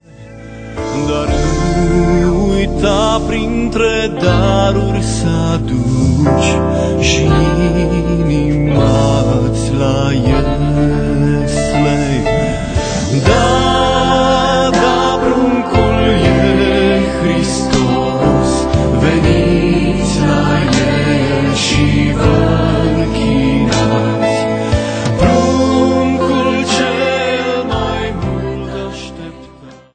bune de adormit (Postat pe 05.12.2008)